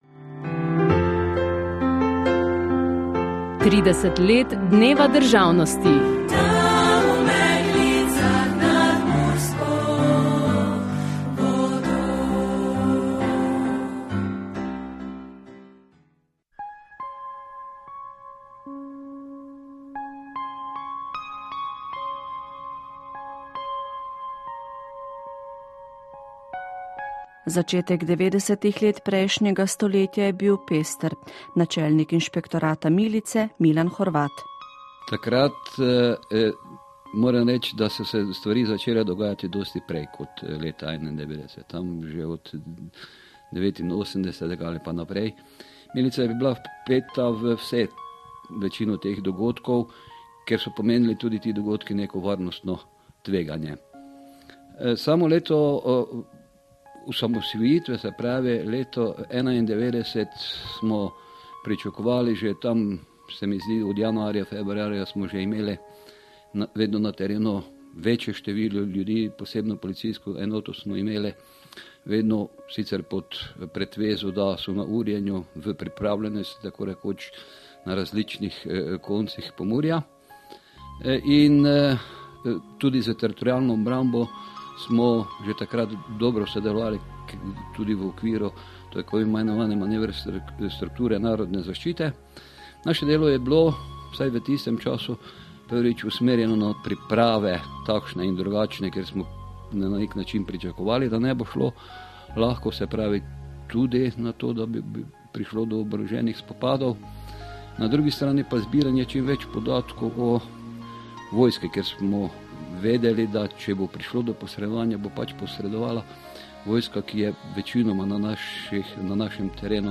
MURSKI VAL REPORTAŽA | OZVVS Murska Sobota
murski-val-reportaza-30-let.mp3